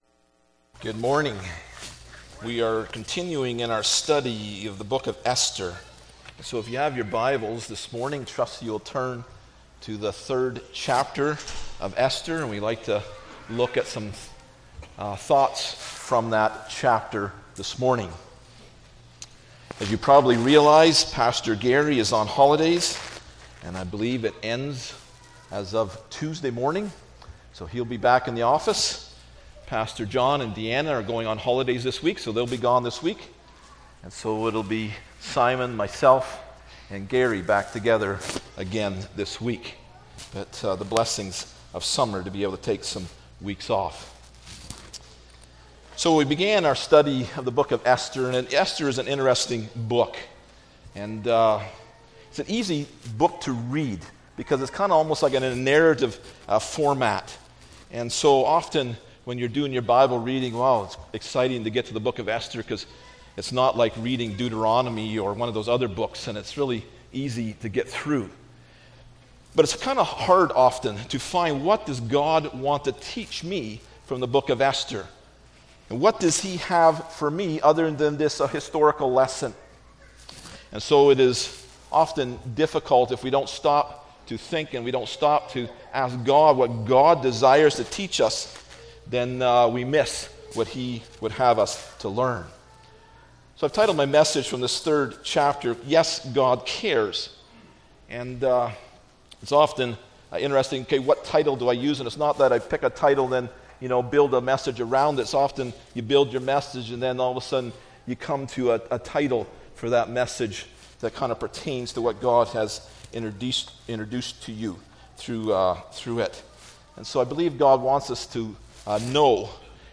Esther 3 Service Type: Sunday Morning Bible Text